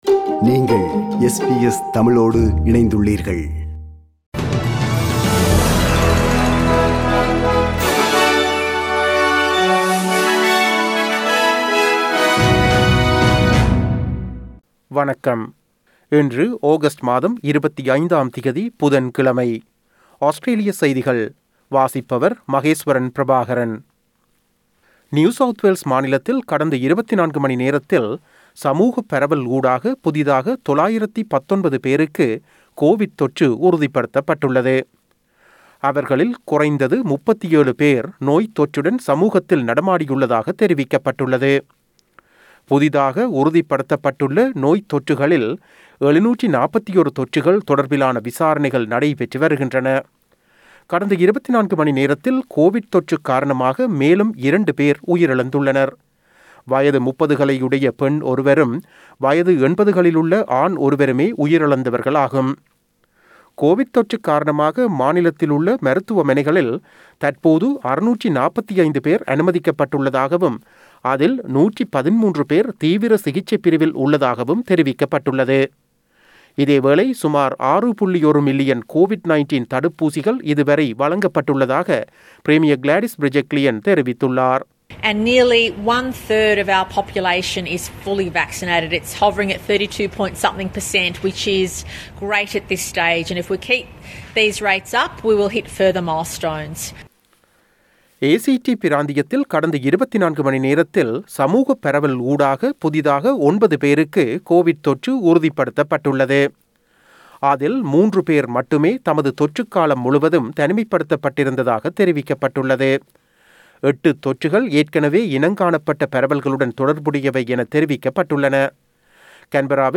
Australian news bulletin for Wednesday 25 August 2021.